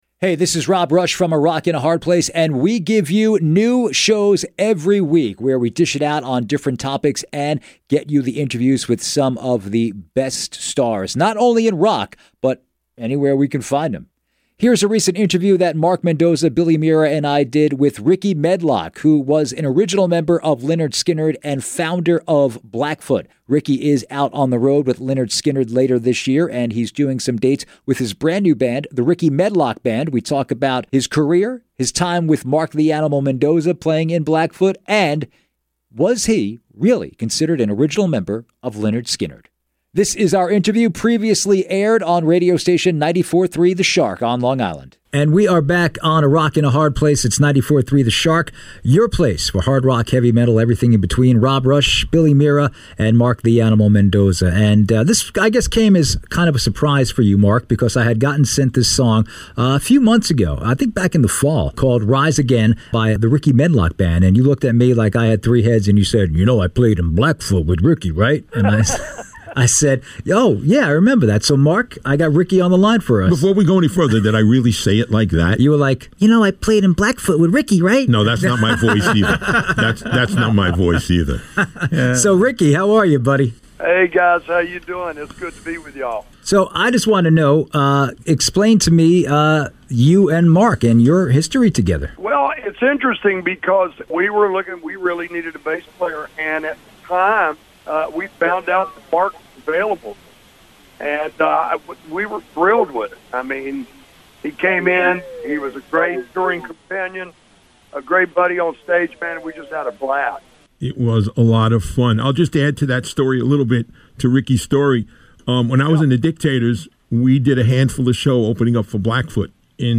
Rickey Medlocke Interview
Headliner Embed Embed code See more options Share Facebook X Subscribe A Rock and A Hard Place Airs Sunday nights on Long Island radio station 94.3 The Shark.